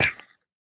Minecraft Version Minecraft Version 1.21.5 Latest Release | Latest Snapshot 1.21.5 / assets / minecraft / sounds / mob / turtle / baby / hurt2.ogg Compare With Compare With Latest Release | Latest Snapshot
hurt2.ogg